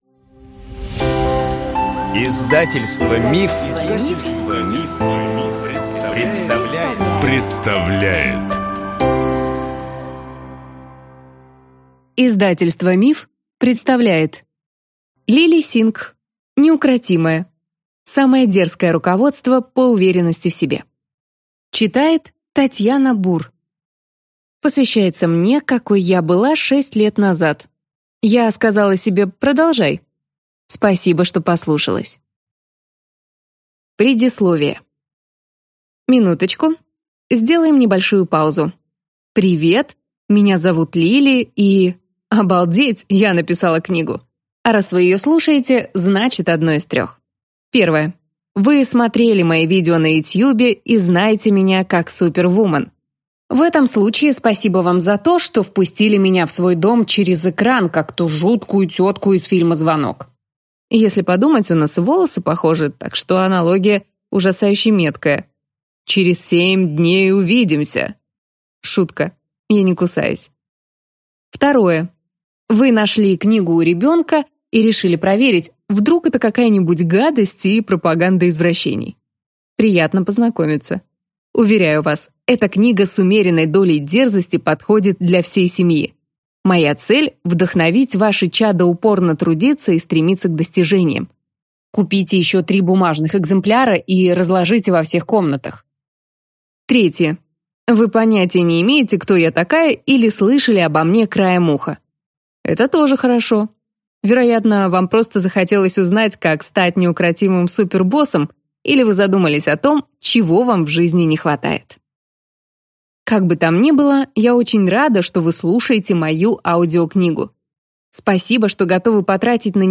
Аудиокнига Неукротимая. Самое дерзкое руководство по уверенности в себе | Библиотека аудиокниг